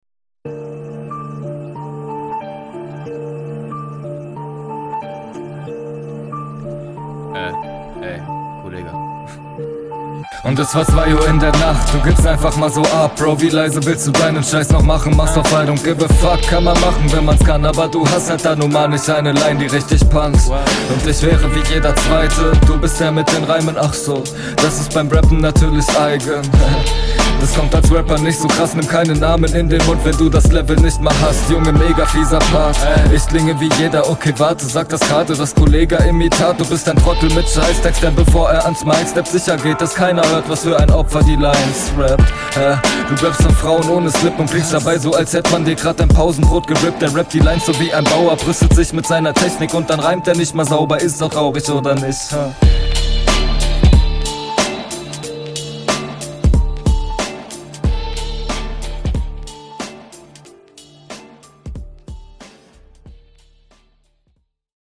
Geiler Einstieg, nice Stimme!
Ja, du kommst hier fresher und deutlicher rüber als dein Gegner.